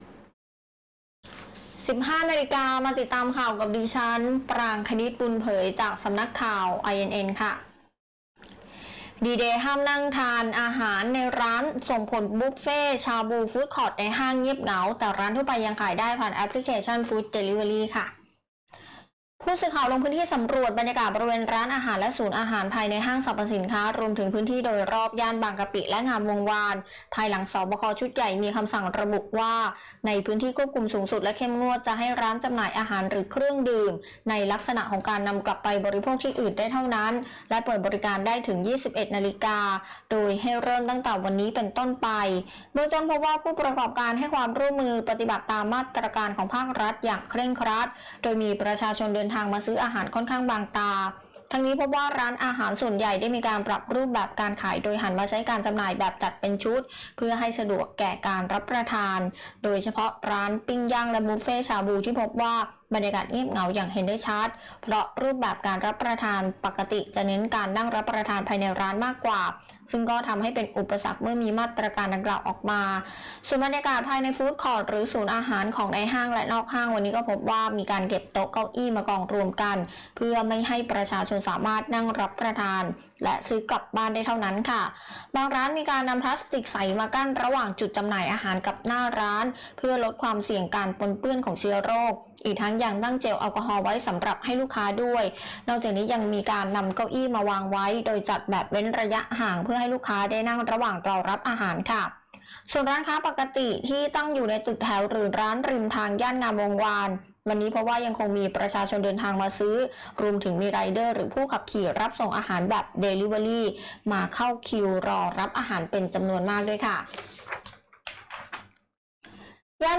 คลิปข่าวต้นชั่วโมง
ผู้สื่อข่าวลงพื้นที่สำรวจบรรยากาศบริเวณร้านอาหารและศูนย์อาหาร ภายในห้างสรรพสินค้า รวมถึงพื้นที่โดยรอบย่านบางกะปิ และ งามวงศ์วาน ภายหลังศบค.ชุดใหญ่มีคำสั่ง ระบุว่า ในพื้นที่ควบคุมสูงสุดและเข้มงวดจะให้ร้านจำหน่ายอาหารหรือเครื่องดื่ม ในลักษณะของการนำกลับไปบริโภคที่อื่นได้เท่านั้น และเปิดบริการได้ถึง 21.00 น. โดยให้เริ่มตั้งแต่วันนี้ ( 1 พ.ค.64) เป็นต้นไป เบื้องต้นพบว่า ผู้ประกอบการให้ความร่วมมือปฏิบัติตามมาตรการของภาครัฐอย่างเคร่งครัดโดยมีประชาชนเดินทางมาซื้ออาหารค่อนข้างบางตา